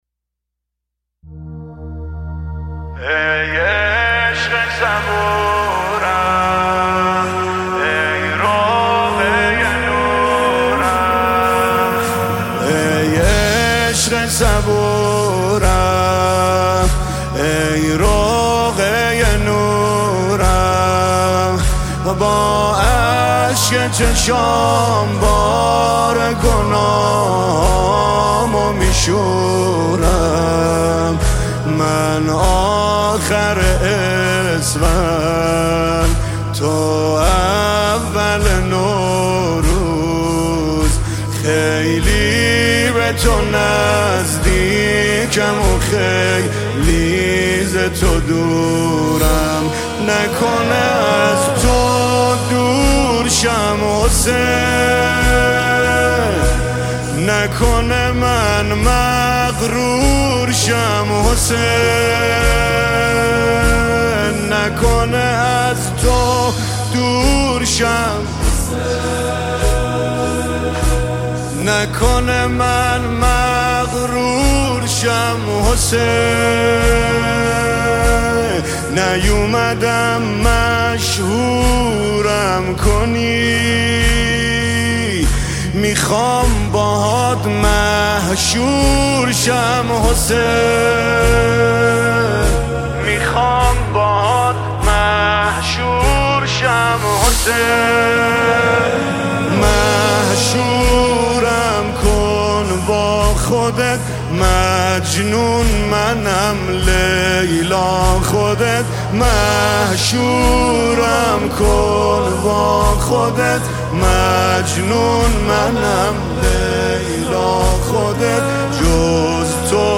نماهنگ دلنشین
مداحی